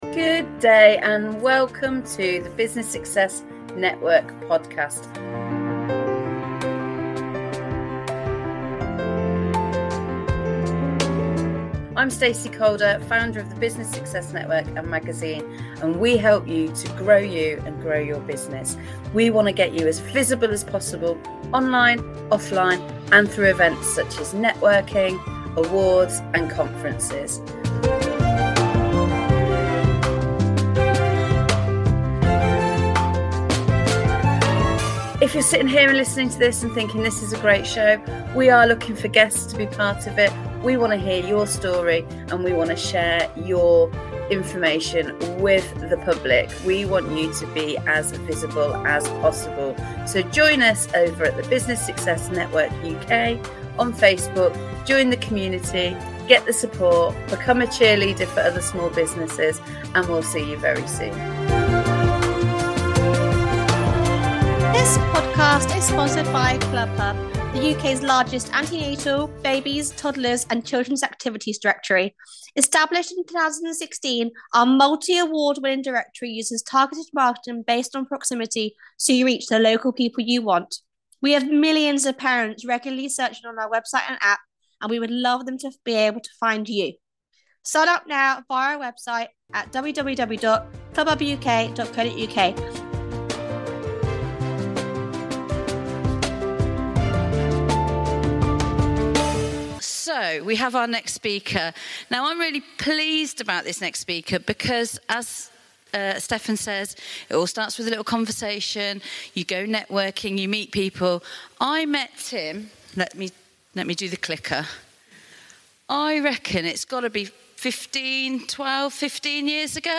Live from Children's Activity Providers Conference